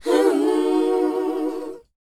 WHOA C#D U.wav